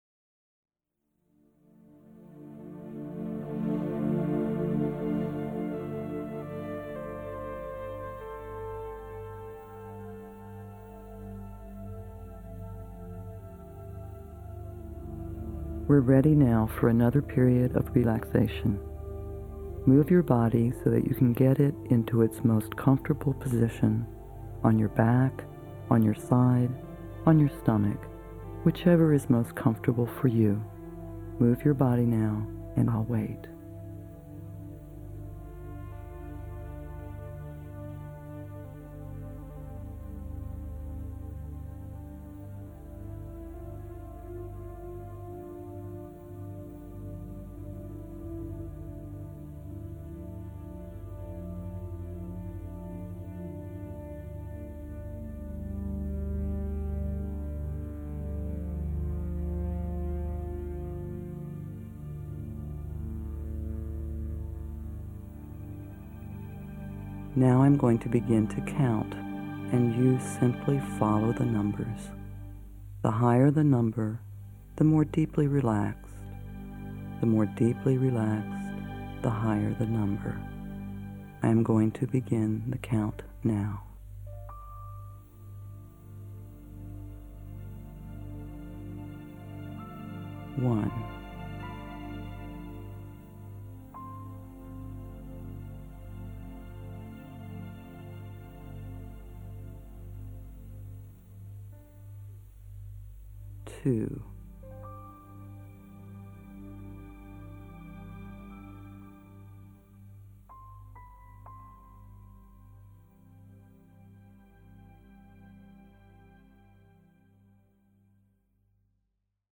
Restore energy and vitality with this verbally guided inner journey.
Use Energy Walk to achieve greater balance and harmony and support the perfect functioning of mind and body. Hemi-Sync® sound patterns facilitate deepening states of relaxation throughout your journey, until you drift gently into a restful sleep.  45 min.